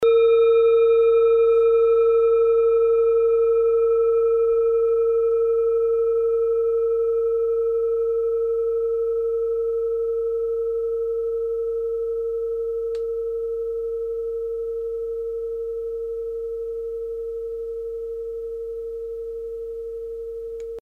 Kleine Klangschale Nr.5
Klangschale-Durchmesser: 11,4cm
Diese Klangschale ist eine Handarbeit aus Bengalen. Sie ist neu und ist gezielt nach altem 7-Metalle-Rezept in Handarbeit gezogen und gehämmert worden.
kleine-klangschale-5.mp3